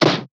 Cartoon Punch Cassette E
2D Animation Cassette Comic Fighting Game Punch SFX sound effect free sound royalty free Movies & TV